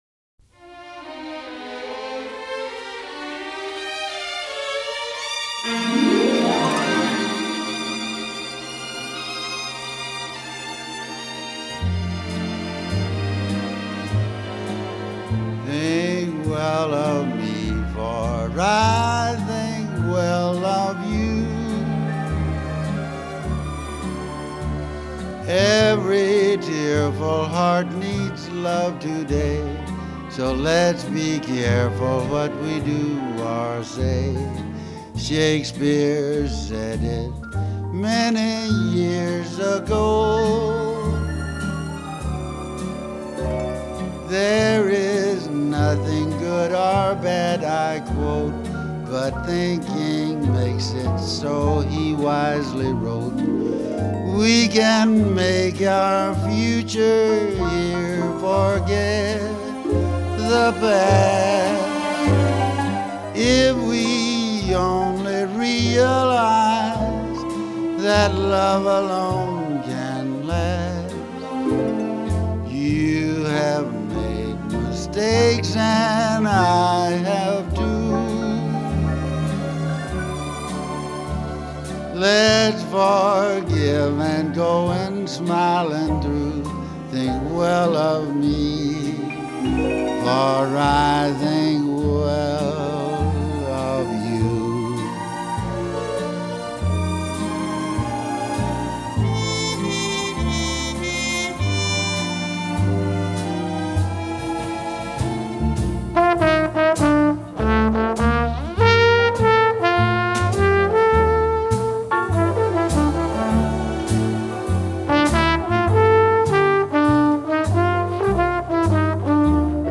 blues/jazz